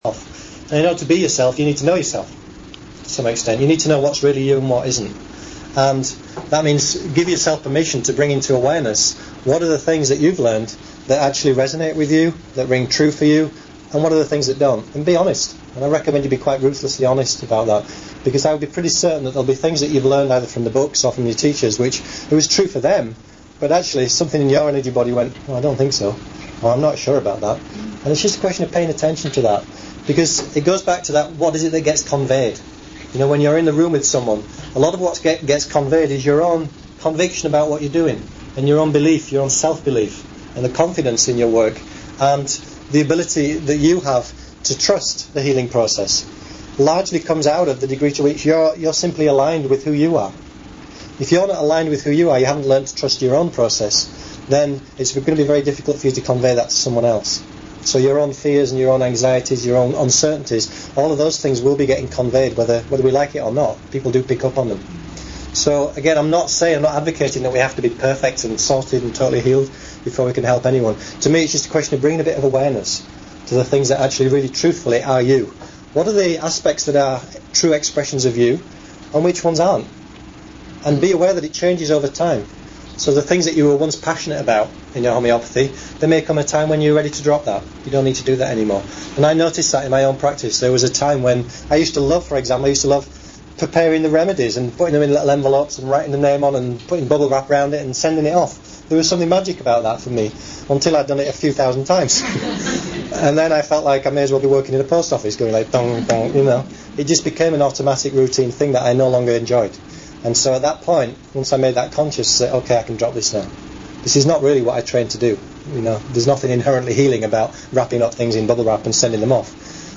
Running a Successful Practice Seminar